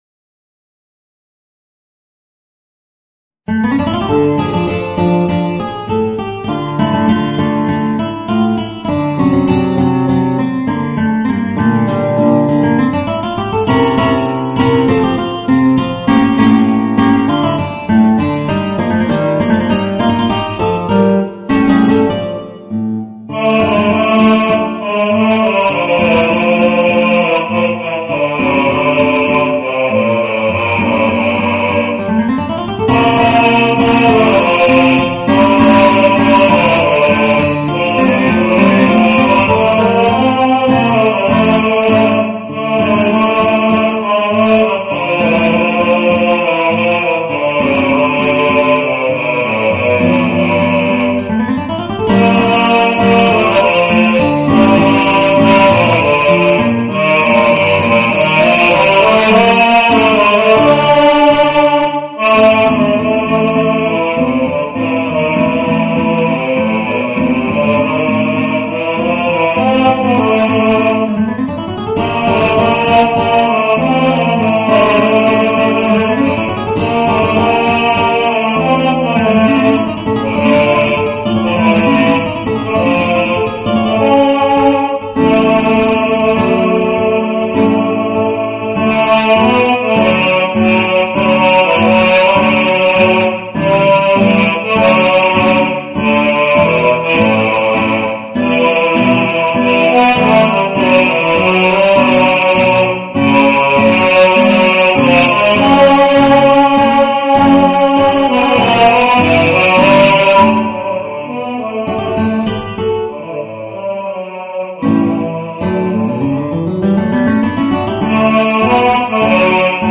Voicing: Baritone